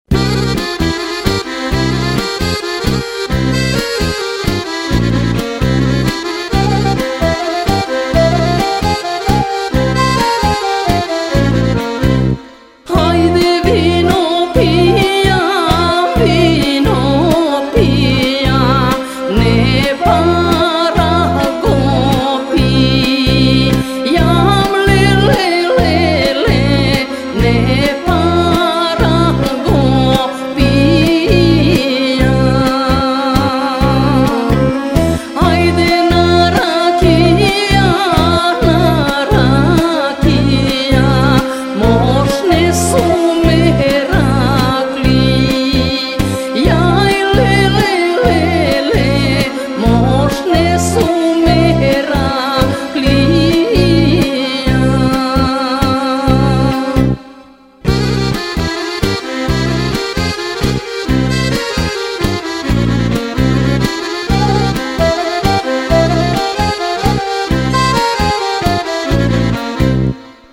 ２０１８年にマケドニアで手に入れた民族音楽のCDに元歌とおぼしきAjde Vino Pijamが入っていた。こちらはUの歌の部分は８＋６の１４小節からできていて心地よい。